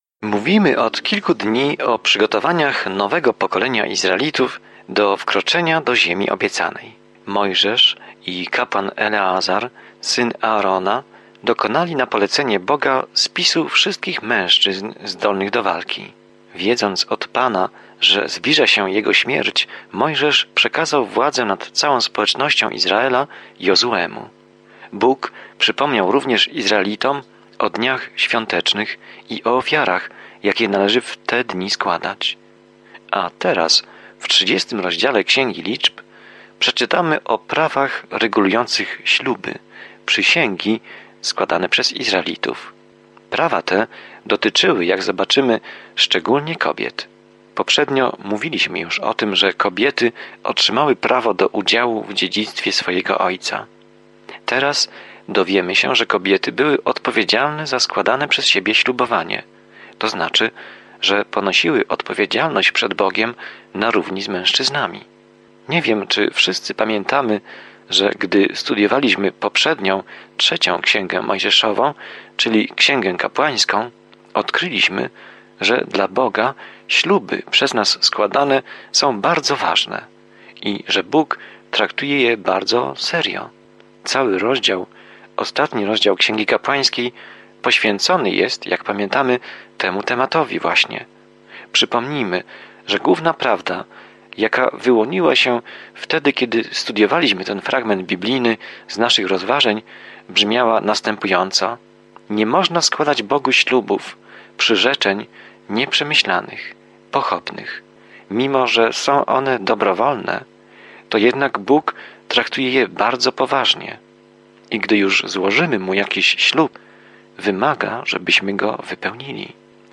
Pismo Święte Liczb 30 Dzień 20 Rozpocznij ten plan Dzień 22 O tym planie W Księdze Liczb spacerujemy, wędrujemy i oddajemy cześć Izraelowi przez 40 lat na pustyni. Codziennie podróżuj po Liczbach, słuchając studium audio i czytając wybrane wersety słowa Bożego.